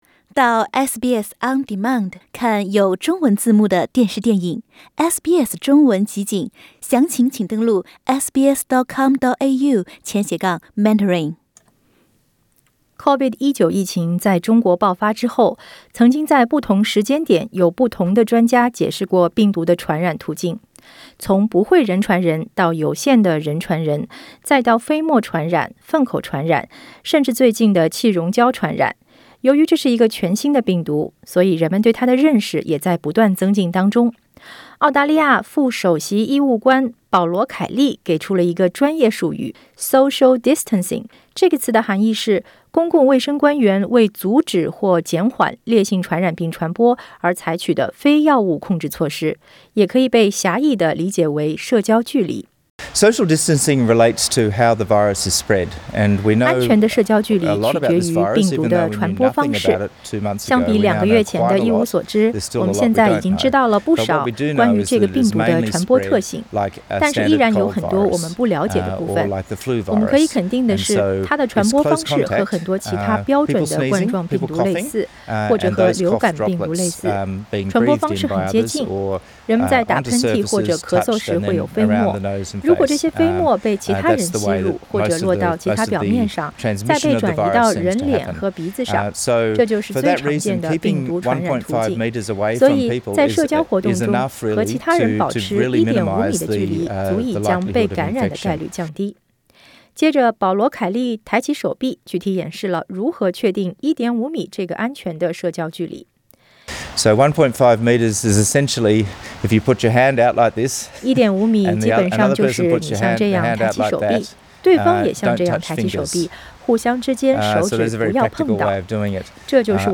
澳大利亚副首席医务官保罗·凯利昨天接受了SBS新闻的采访，具体演示了预防病毒感染的关键点之一——Social Distancing。